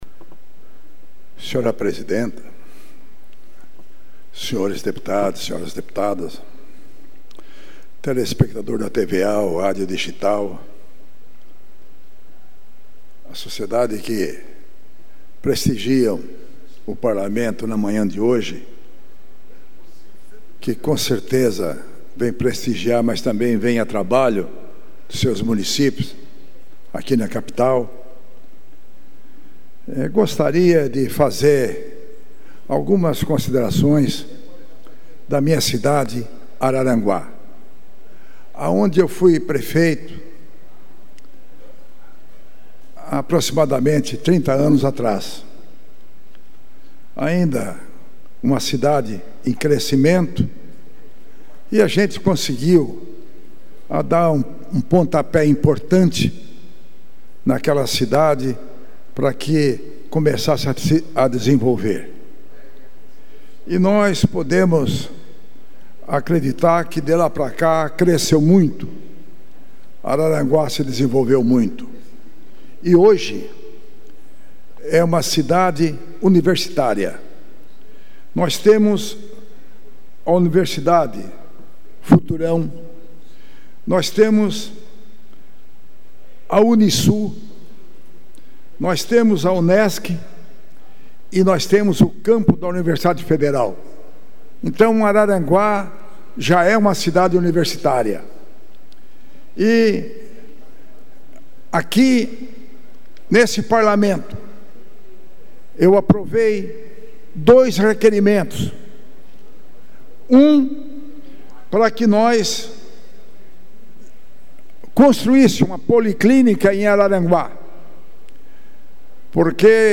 Pronunciamentos dos deputados na sessão desta quinta (30)
Confira aqui as manifestações dos parlamentares em tribuna, durante a sessão ordinária desta quinta-feira (30):